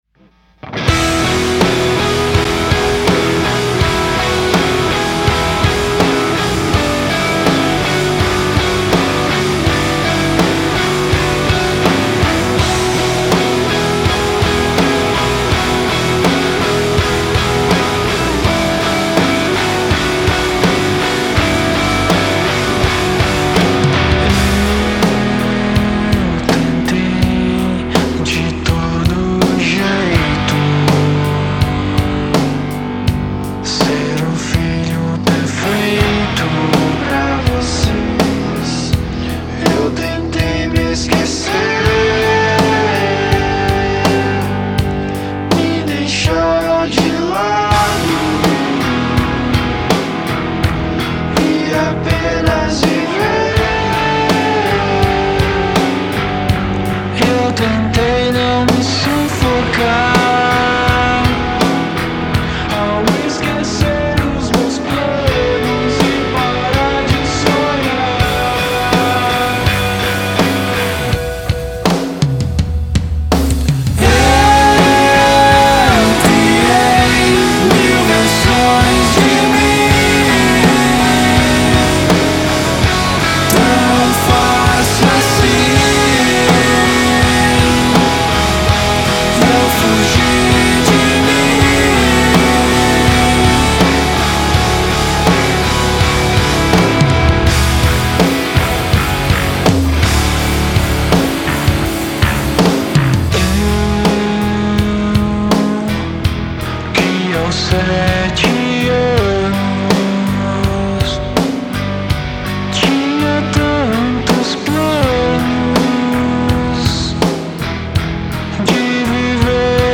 EstiloEmocore